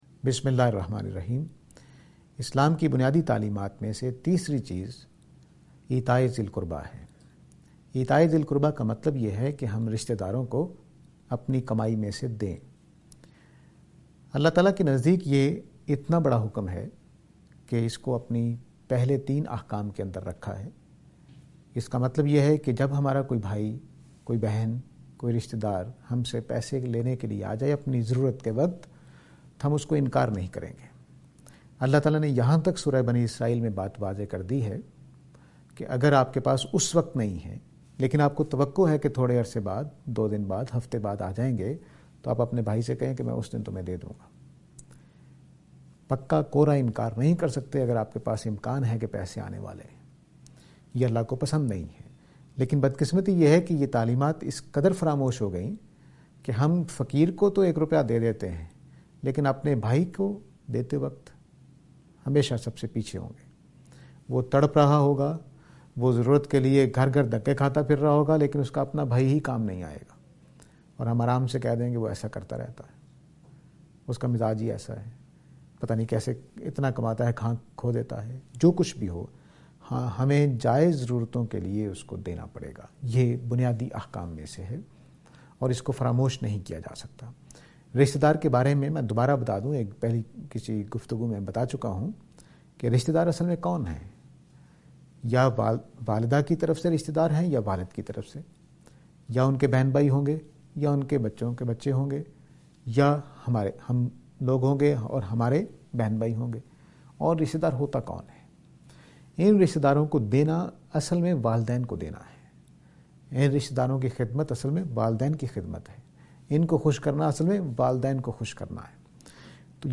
This lecture is and attempt to answer the question "Financial Help of Relatives".